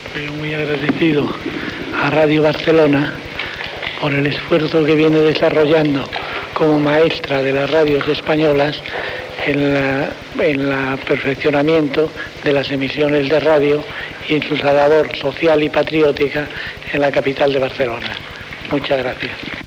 Paraules del cap d'Estat Generalísimo Francisco Franco a Ràdio Barcelona durant la seva visita a Barcelona.